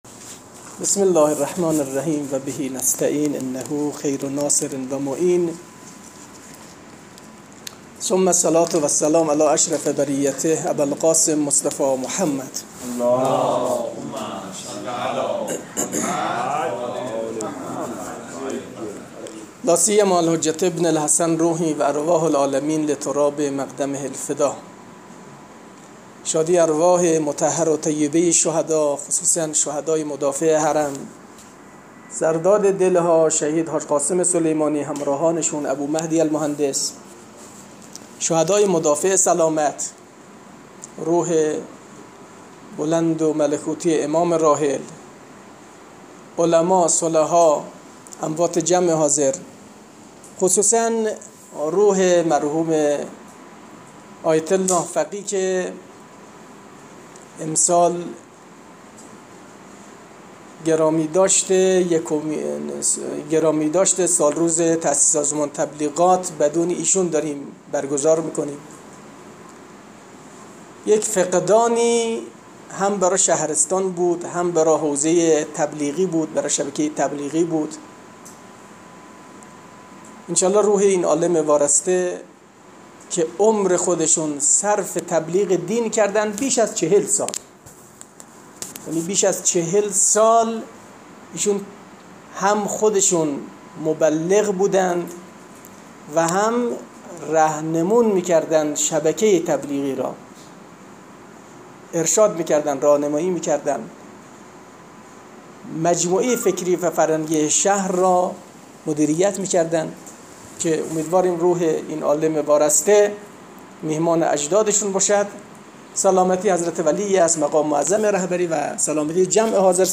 سخنرانی به مناسبت سالروز تاسیس سازمان تبلیغات اسلامی.mp3
سخنرانی-به-مناسبت-سالروز-تاسیس-سازمان-تبلیغات-اسلامی.mp3